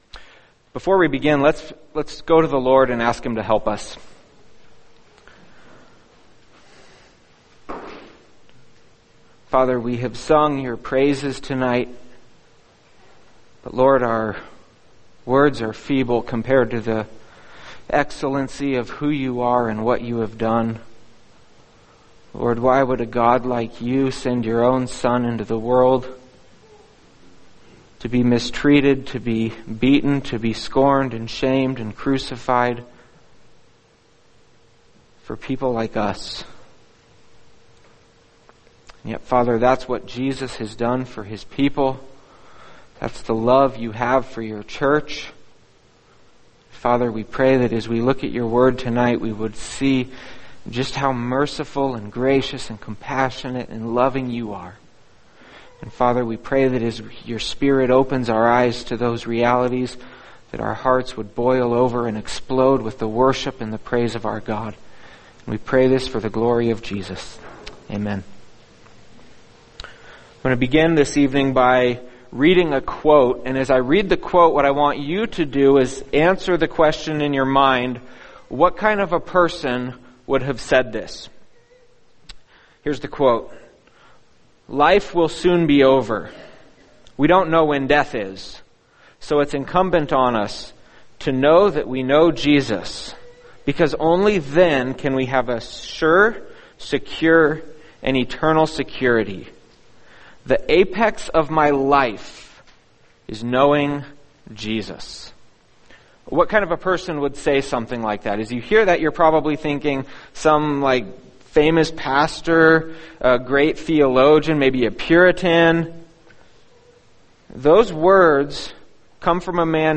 [sermon] Hebrews 11:31 A Prostitute Changes Professions | Cornerstone Church - Jackson Hole